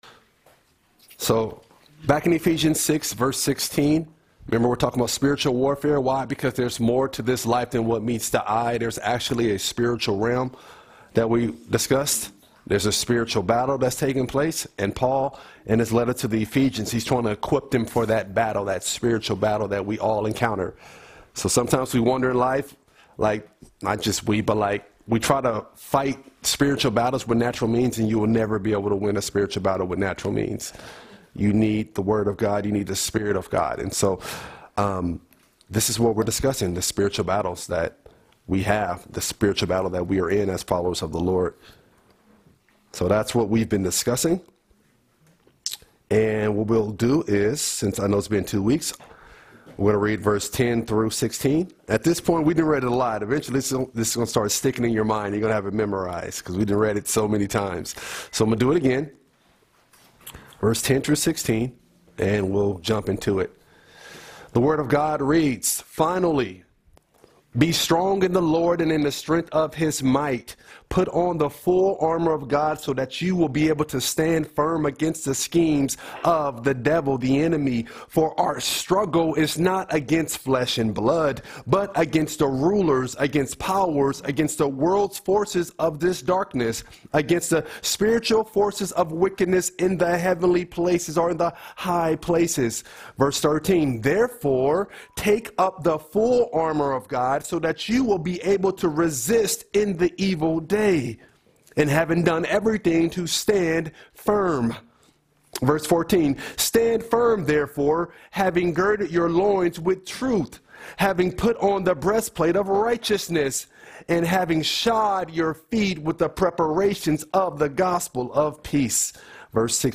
In this sermon, we continue looking at the Shield of Faith from Ephesians 6:16, reminding us that spiritual battles aren’t won by human strength but through God’s power.